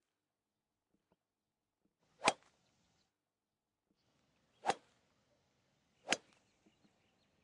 高尔夫练习挥杆
描述：高尔夫练习挥杆与司机和铁杆俱乐部完成。快速摇摆剪草。
标签： 泰勒梅 罢工 秋千 俱乐部 回声 速度快 只是 驾驶 平安 俱乐部 剪辑 RBZ 高尔夫
声道立体声